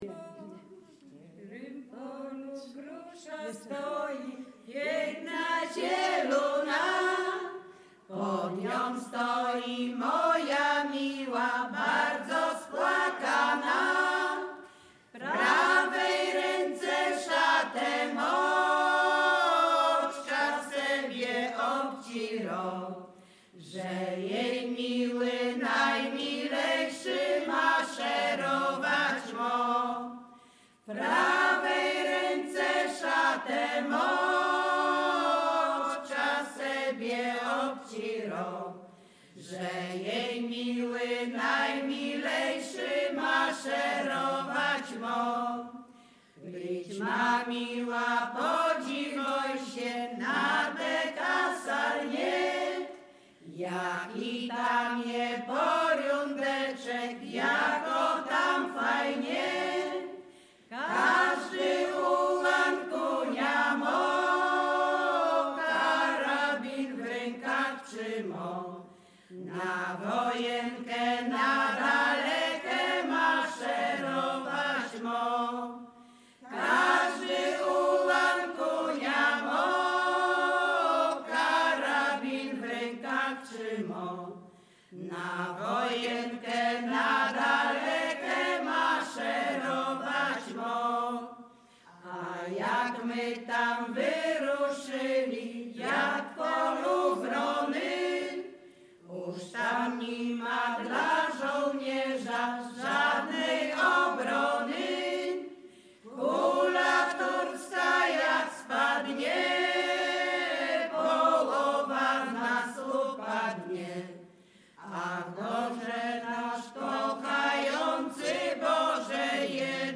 Zespół Dunawiec
Bukowina Rumuńska
Górale Czadeccy
liryczne miłosne wojenkowe rekruckie